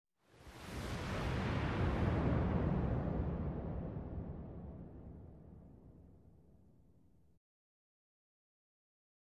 Sports Wave Whoosh
Arena Crowd; Wave Whoosh For Crowd Transition.